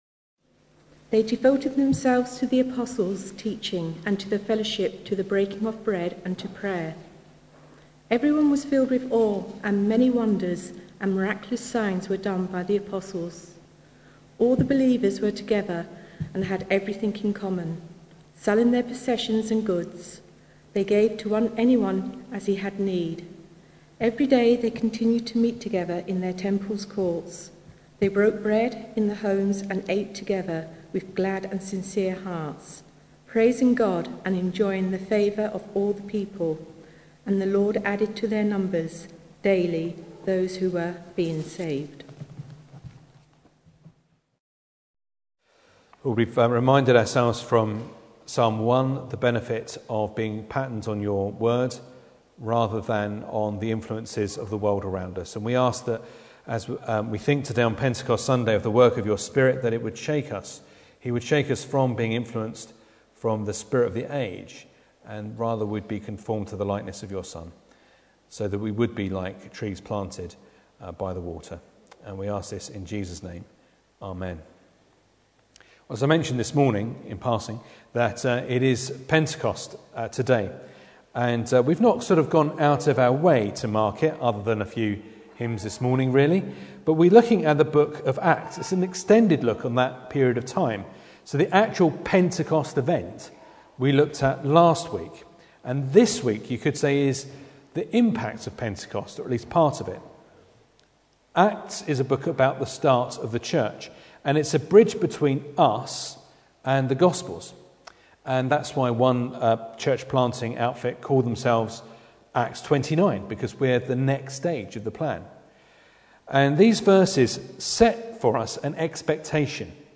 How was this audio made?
Acts 2:42-47 Service Type: Sunday Evening Bible Text